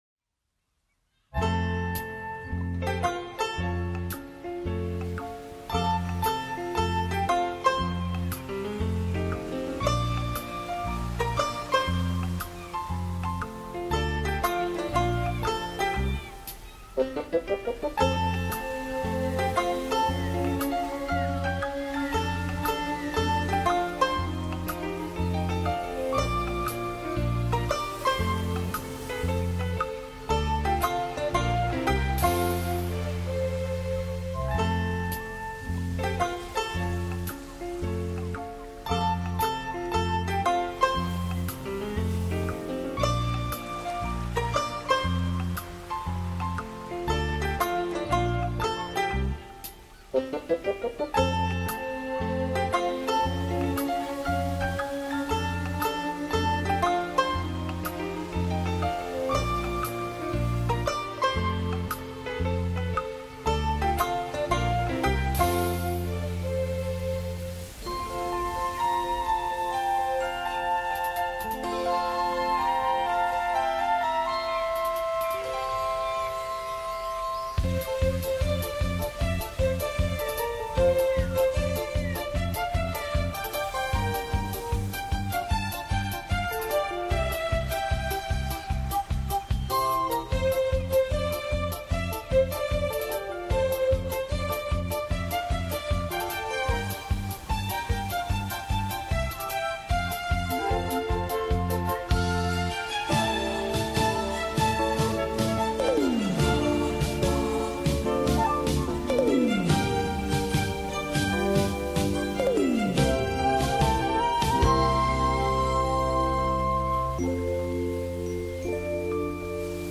your ultimate destination for calming vibes, chill beats
lo-fi music